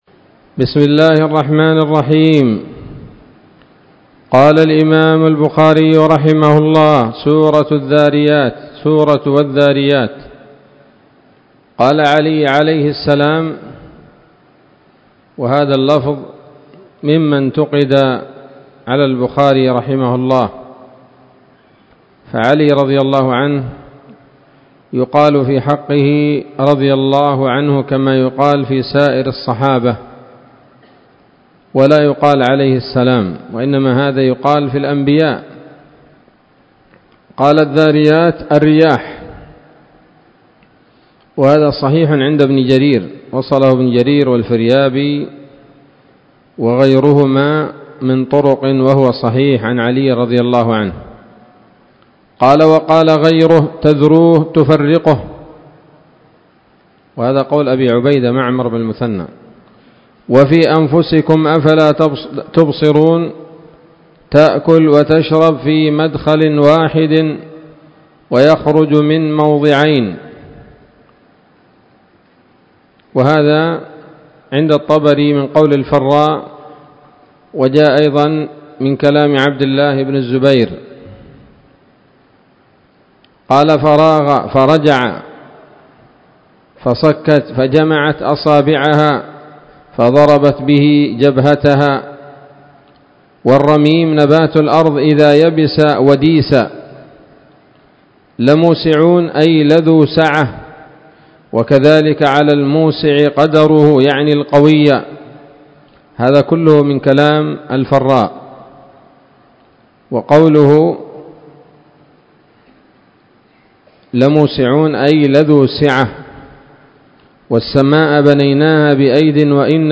الدرس الأربعون بعد المائتين من كتاب التفسير من صحيح الإمام البخاري